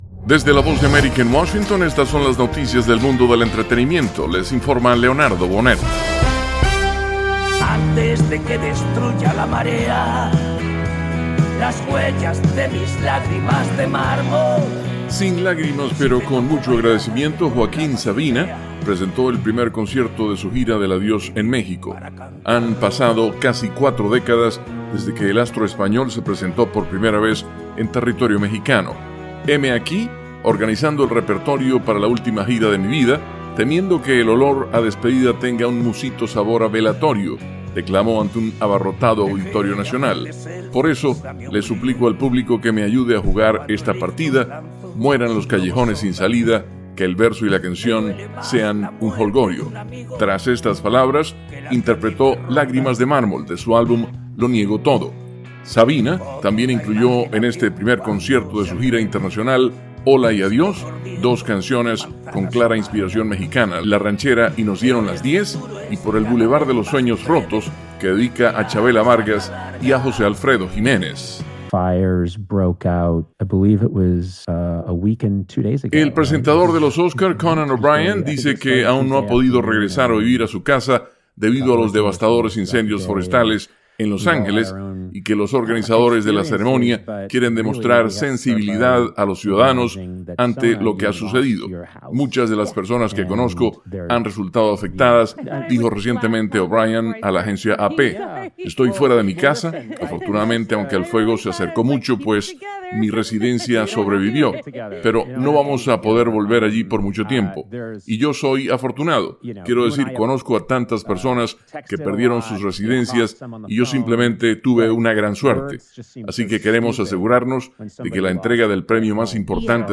Son las Noticias del Mundo del Entretenimiento